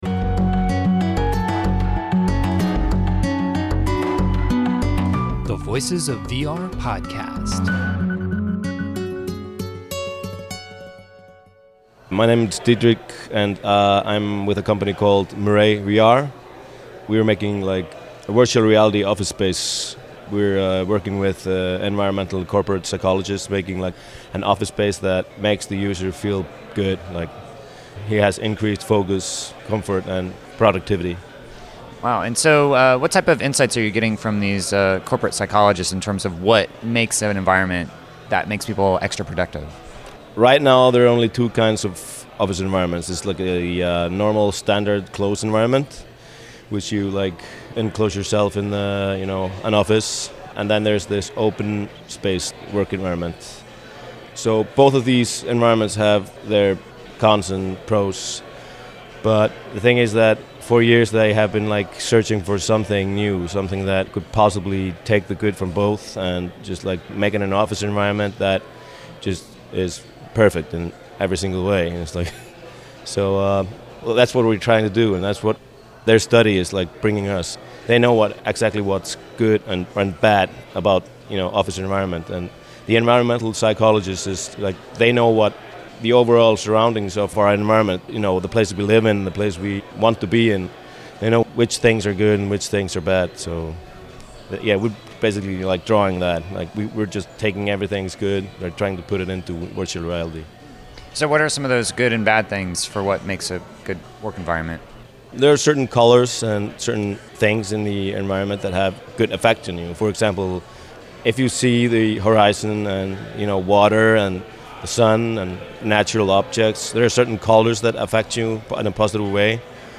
Listen in to our conversation we explore using VR to take breaks and increase productivity within your virtual workplace environment. http